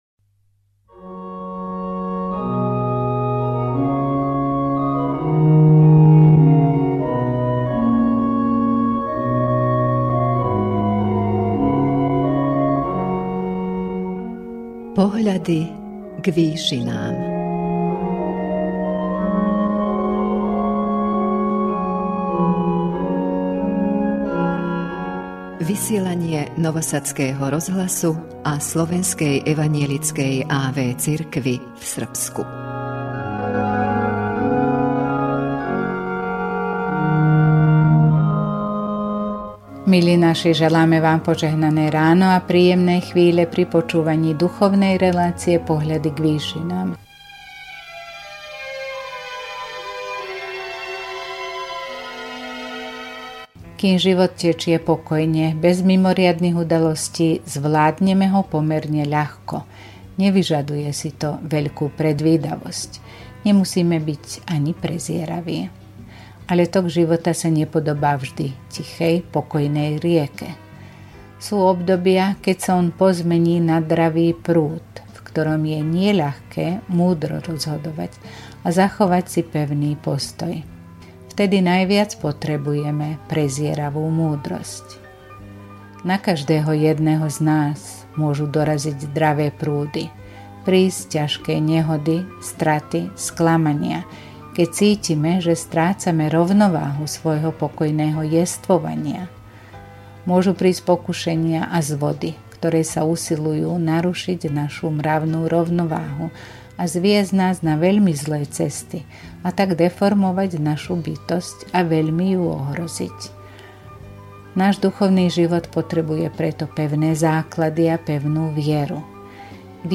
V duchovnej relácii Pohľady k výšinám Rádia Nový Sad a Slovenskej evanjelickej a.v. cirkvi v Srbsku v túto 9. nedeľu po Svätej Trojici duchovnú úvahou